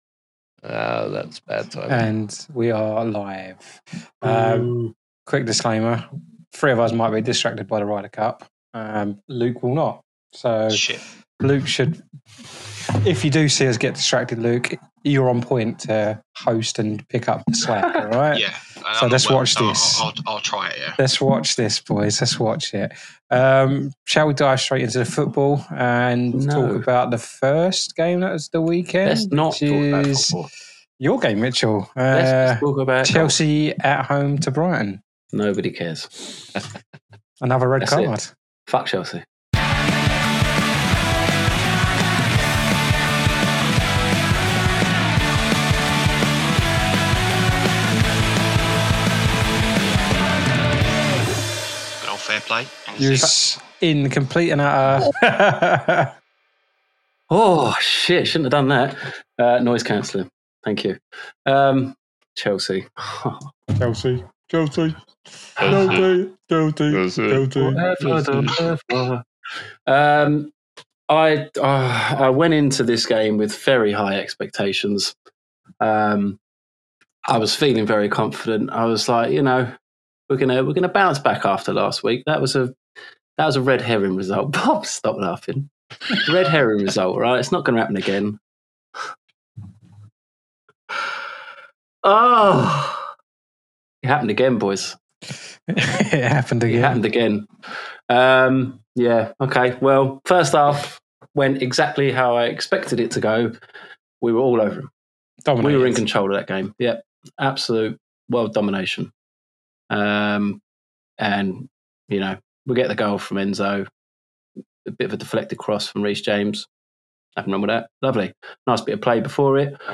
London Is... | A football podcast featuring four friends dissecting all things Arsenal, Chelsea, West Ham, and Spurs.